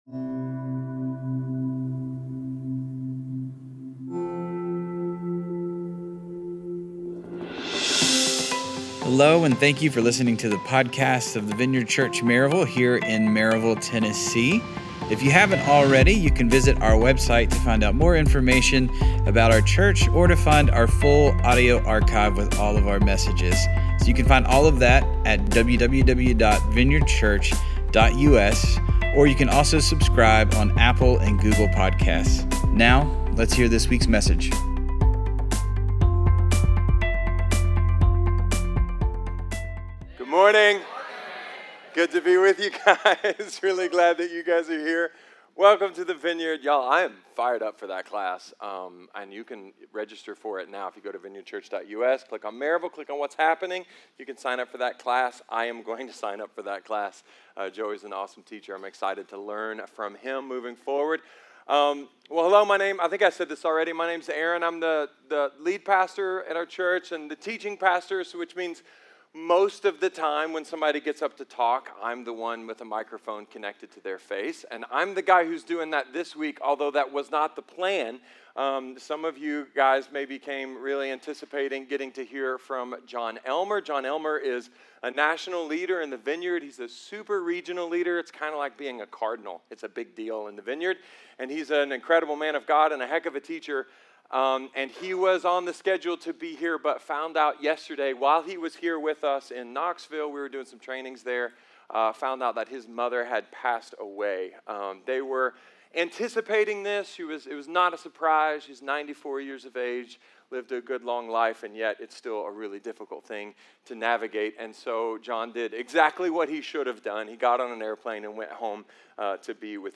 A message from the series "Love Your Neighbor."